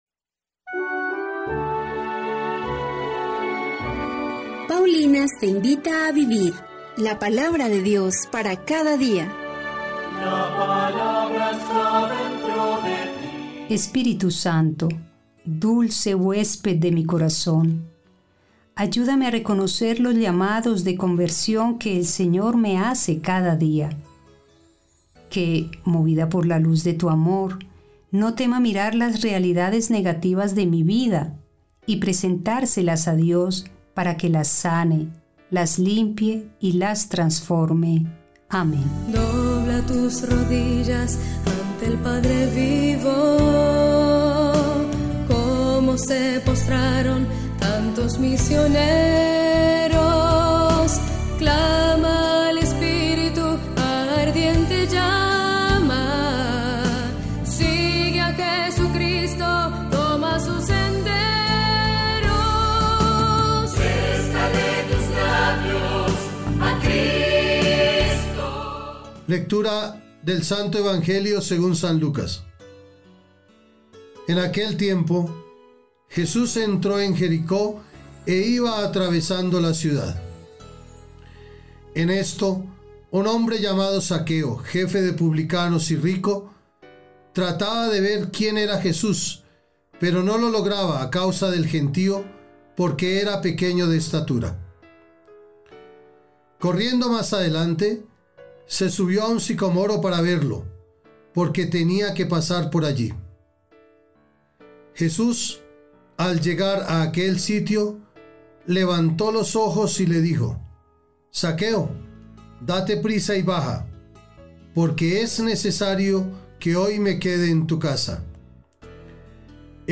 Liturgia del día